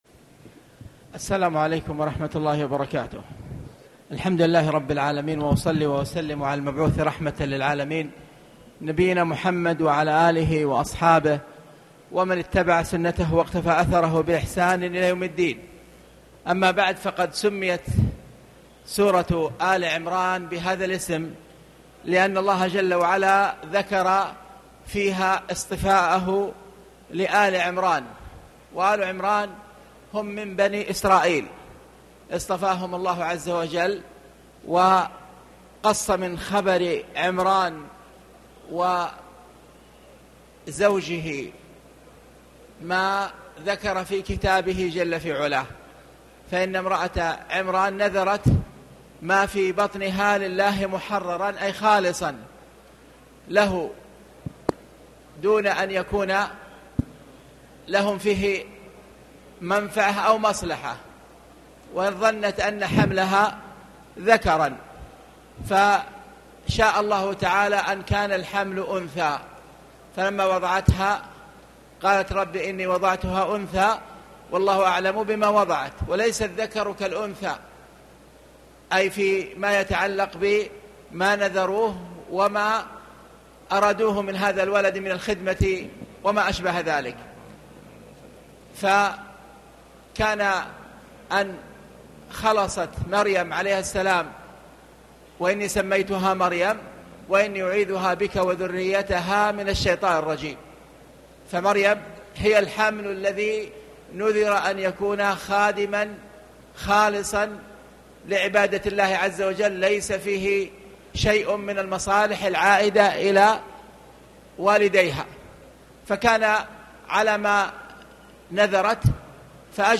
تاريخ النشر ١٢ رمضان ١٤٣٨ هـ المكان: المسجد الحرام الشيخ